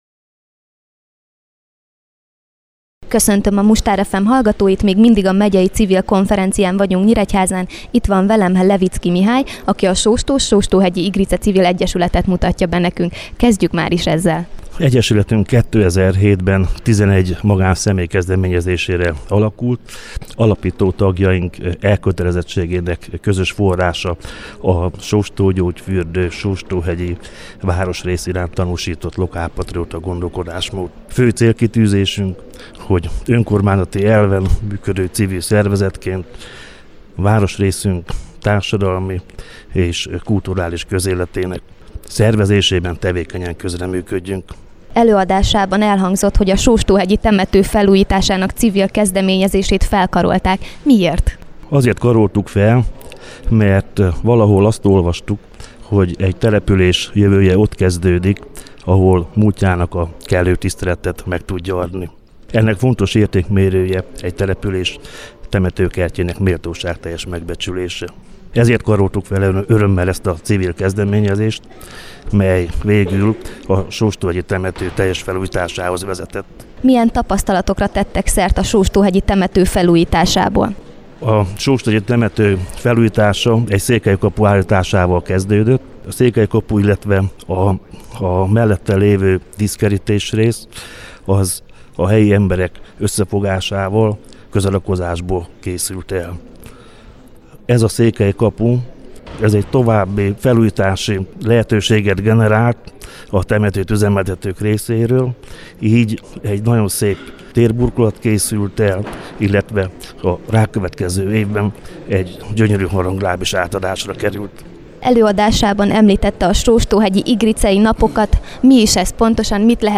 Interjú a konferencián elhangzott előadásról - a Sóstó-Sóstóhegyi Igrice Civil Egyesület bemutatkozása.